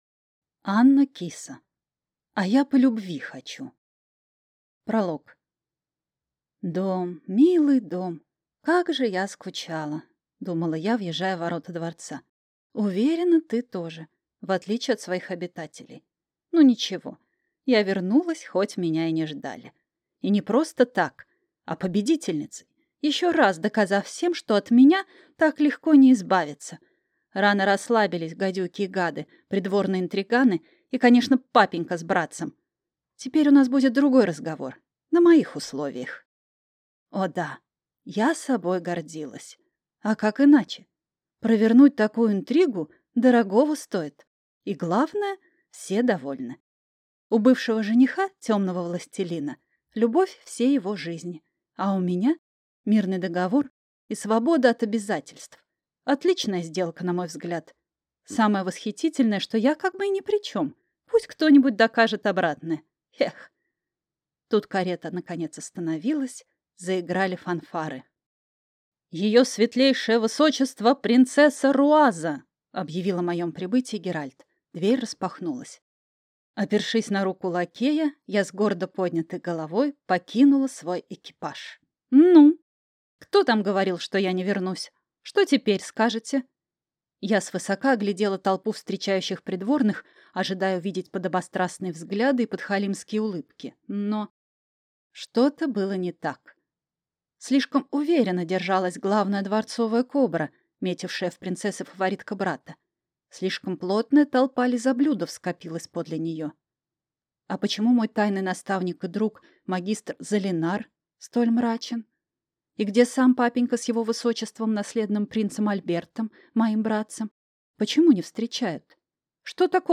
Аудиокнига А я по любви хочу!